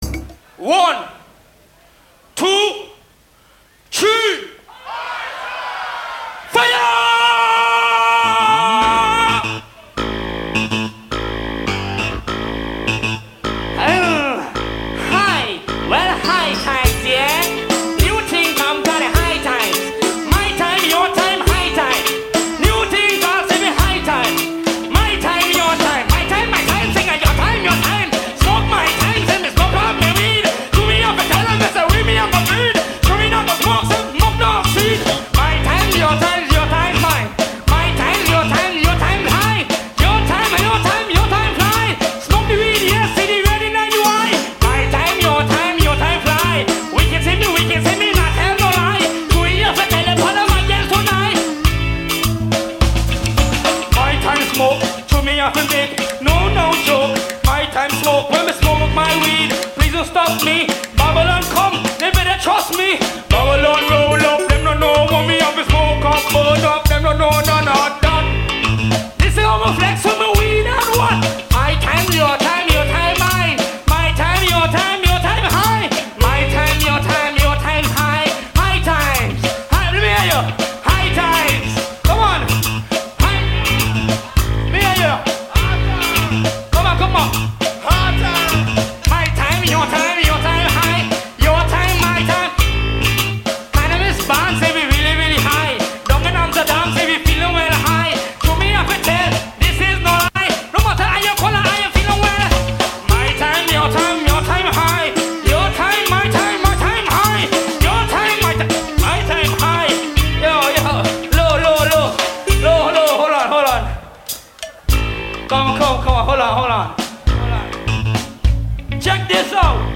Voici un un lien vers le fichier audio pour le télécharger. classé dans : art sonore